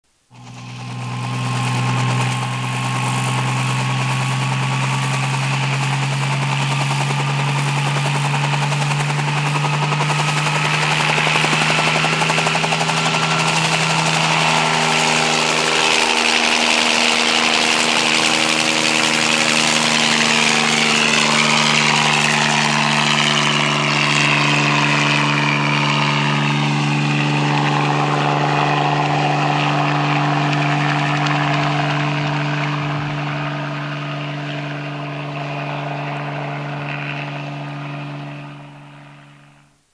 Категория: Различные звуковые реалтоны